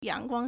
yángguāng
yang2guang1.mp3